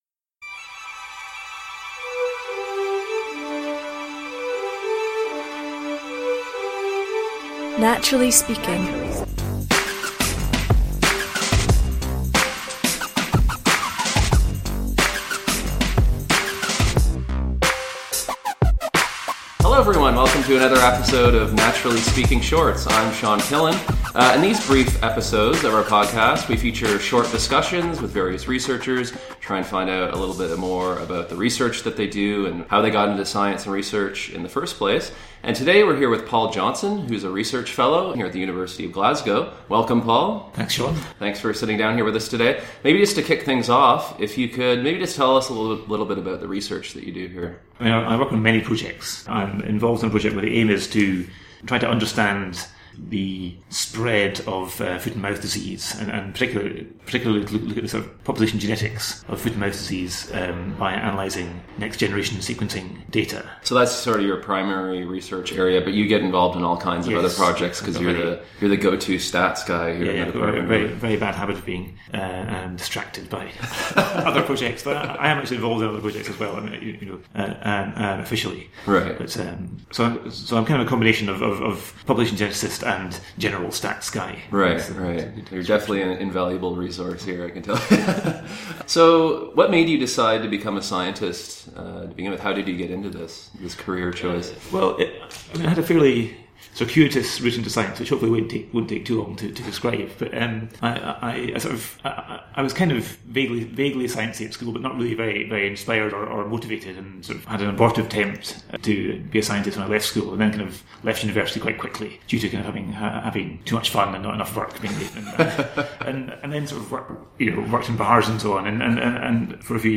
Episode 27 – Adventures in Statistics: An Interview